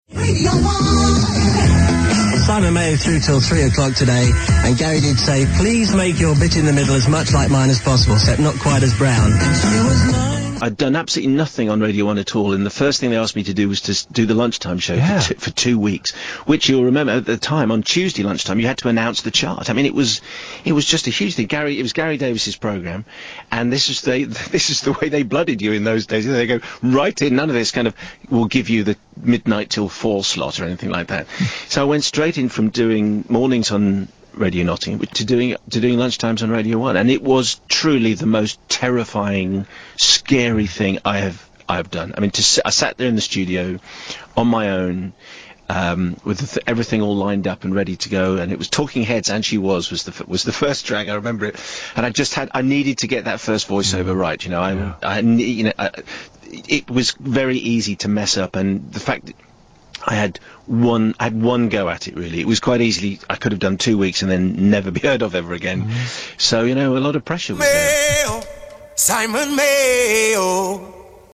Hear here as he delivers his first link on Britain's Favourite; hotfoot from BBC local radio - a transition made by few.